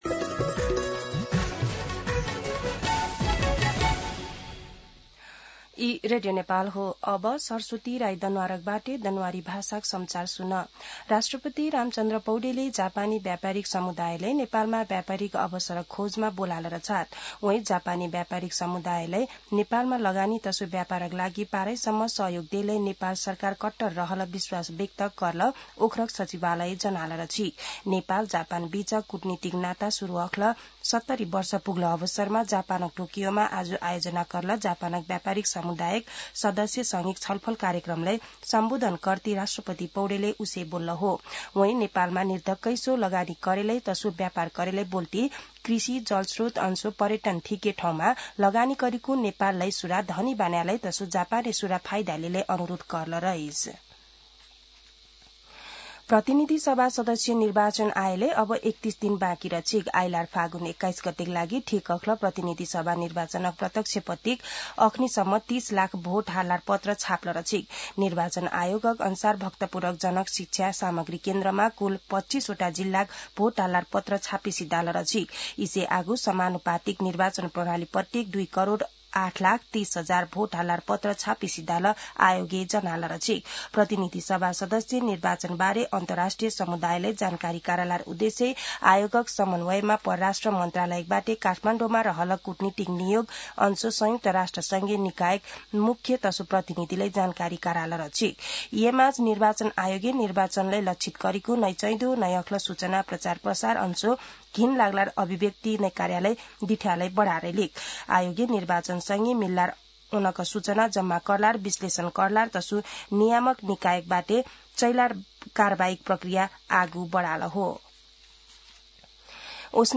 दनुवार भाषामा समाचार : १९ माघ , २०८२
Danuwar-News-19.mp3